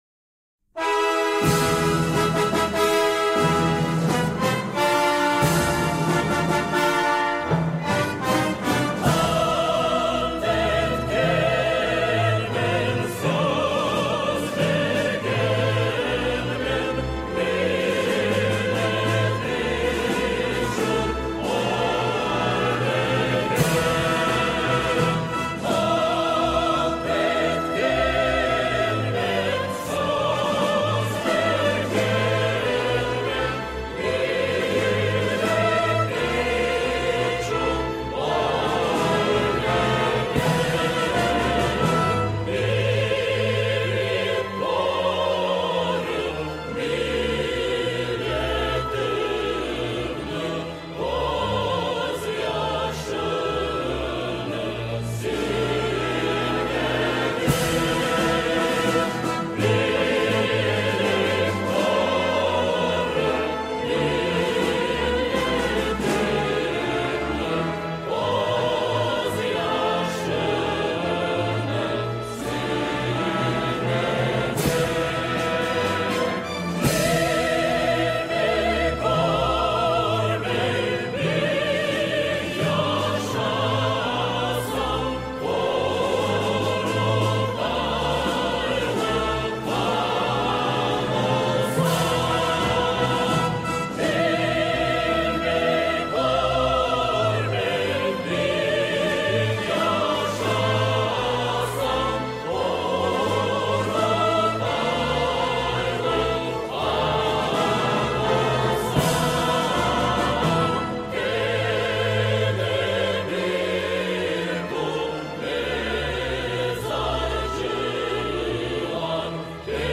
• Качество: высокое
Мелодия передает глубину традиций и любовь к родной земле.
со словами